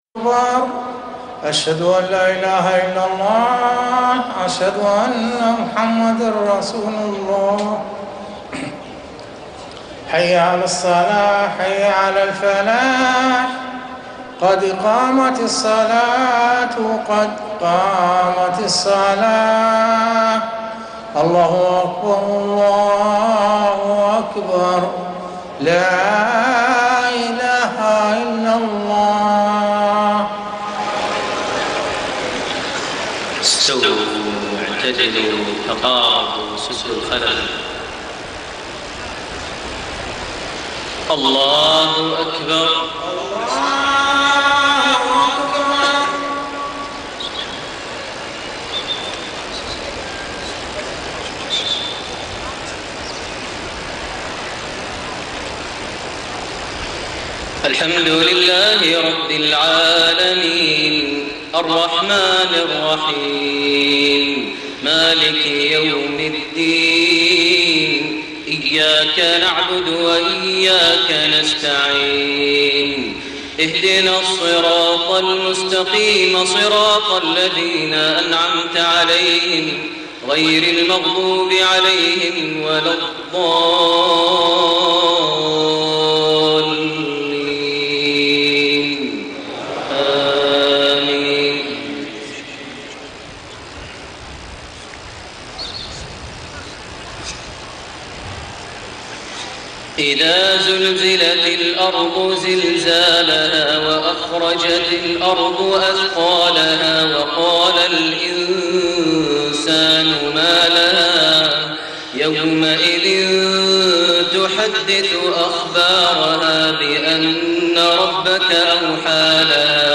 أول فرض | صلاة المغرب ٢٥ جماد الآخر ١٤٢٨هـ لسورتي الزلزلة / القارعة > أول صلاة للشيخ ماهر المعيقلي في المسجد الحرام ١٤٢٨هـ > المزيد - تلاوات ماهر المعيقلي